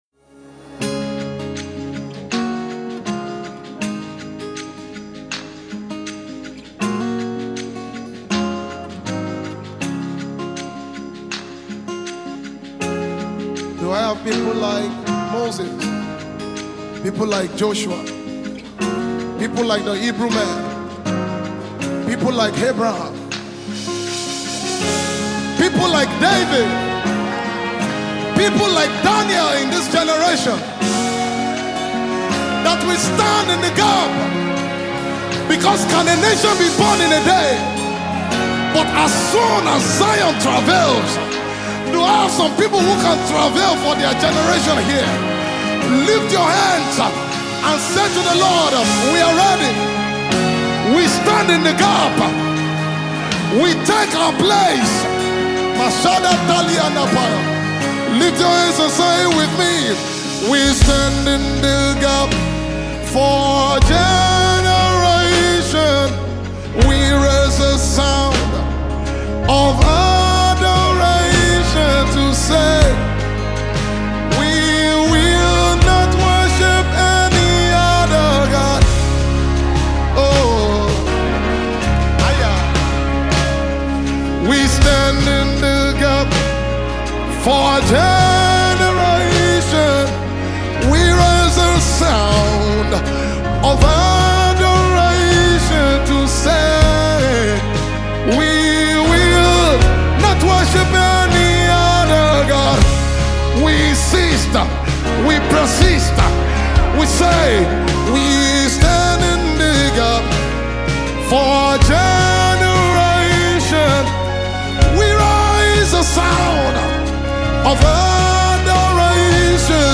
a soulful melody that lifts your spirit,
create a smooth melody that is both calming and uplifting.
Genre: Gospel